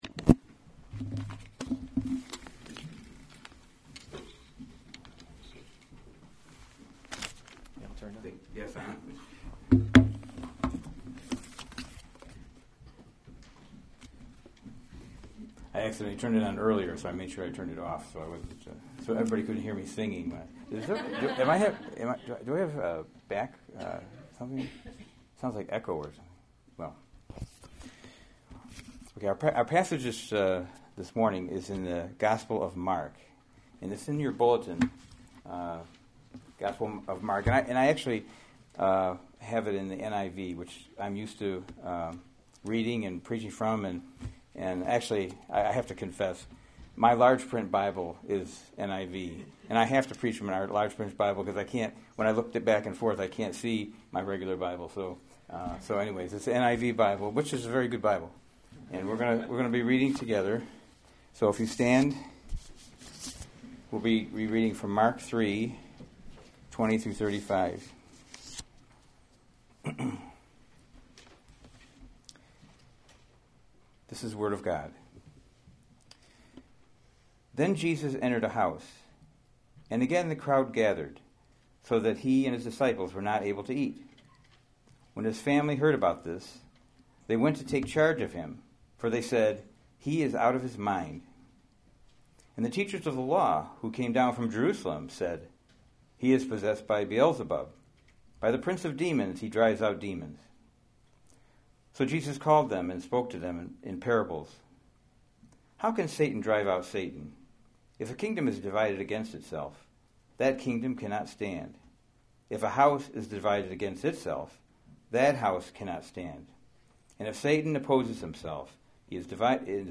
November 12, 2016 Guest Speaker Sermons series Weekly Sunday Service Save/Download this sermon Mark 3:20-35 Other sermons from Mark 20 Then he went home, and the crowd gathered again, so that […]